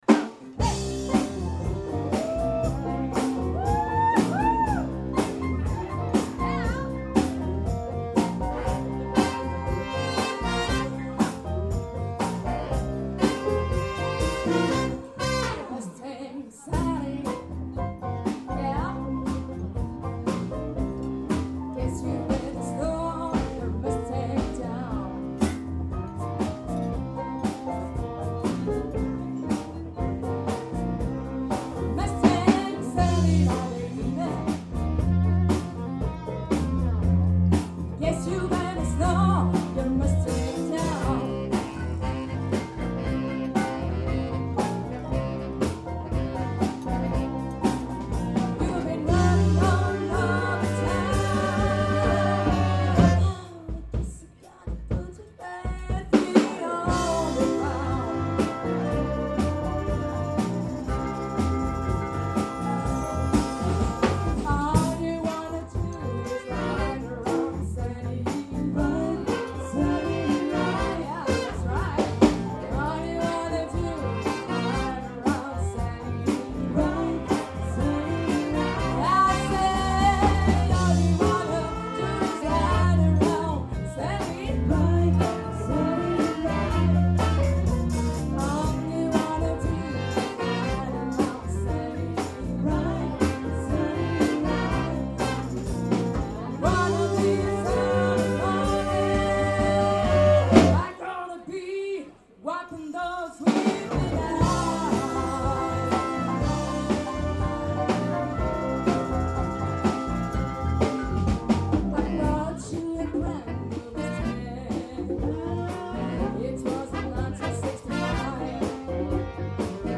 Big  Band - Rythm'n Blues